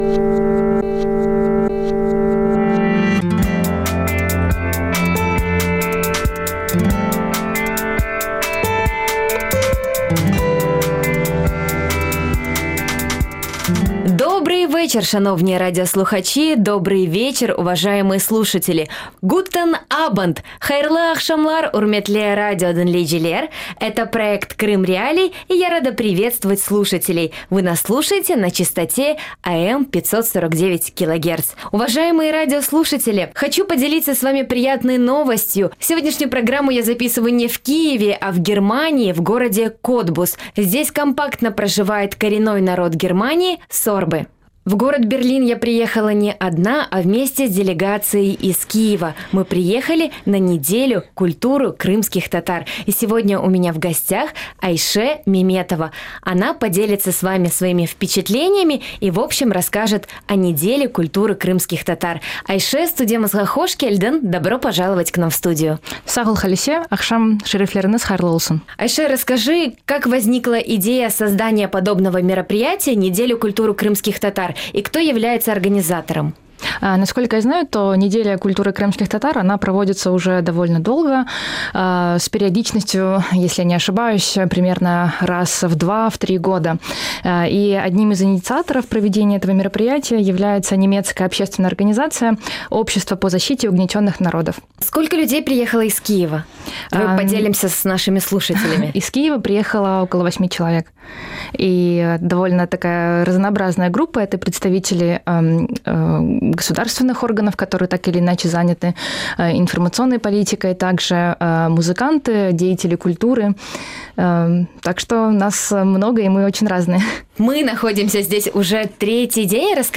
Програма звучить в ефірі Радіо Крим.Реалії. Це новий, особливий формат радіо.